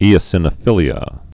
(ēə-sĭnə-fĭlē-ə)